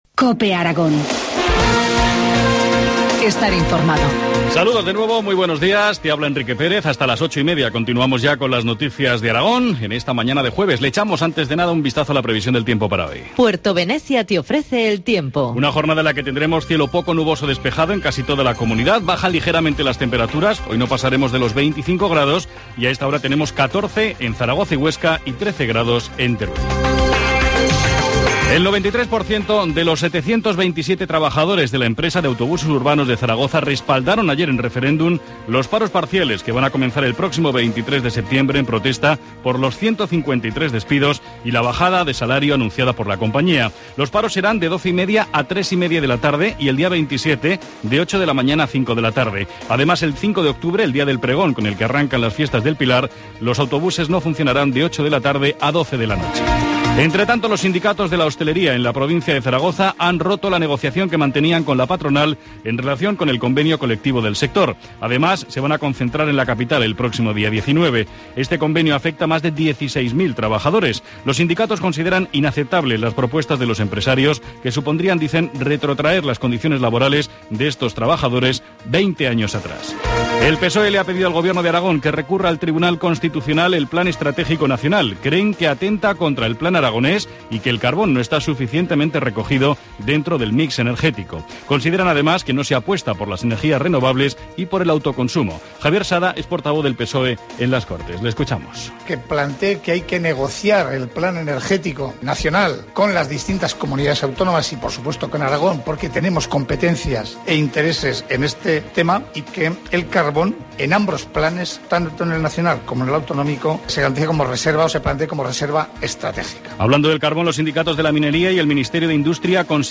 Informativo matinal, jueves 12 de septiembre, 8.25 horas